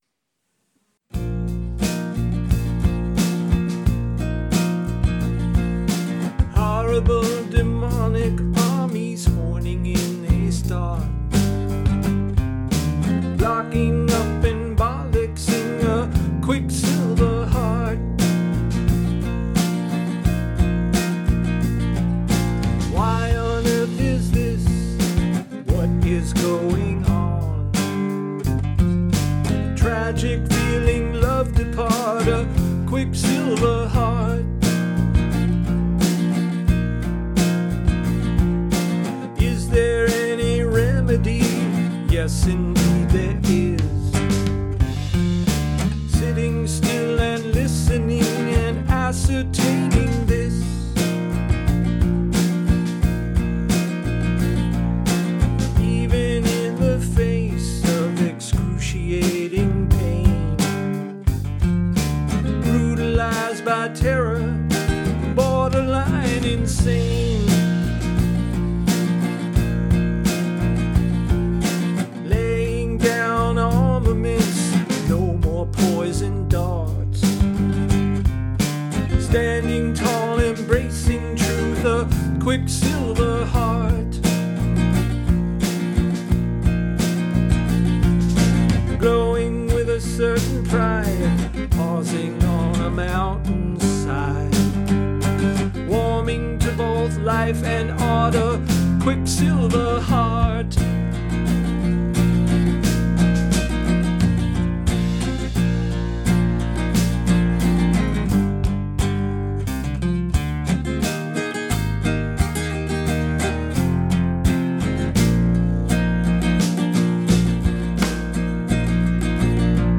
Genre: Singer Songwriter.